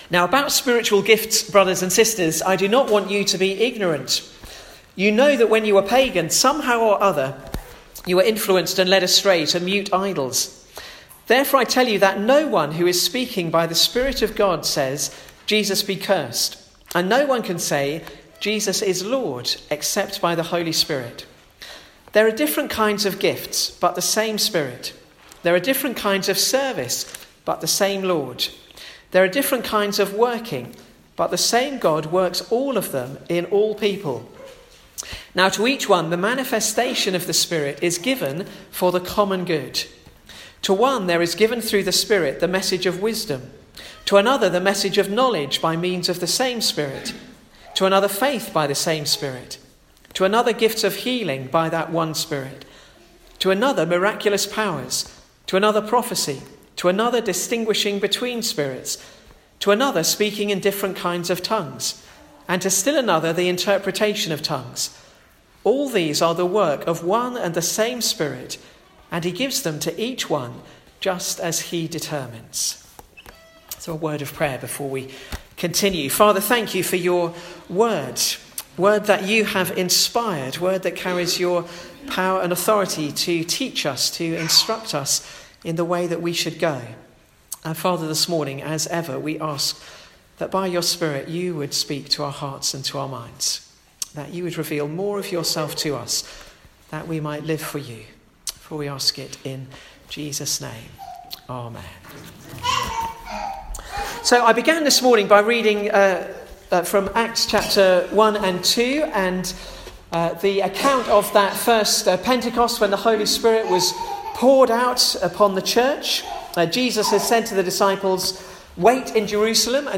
Sermons
Location: Bolney Village Chapel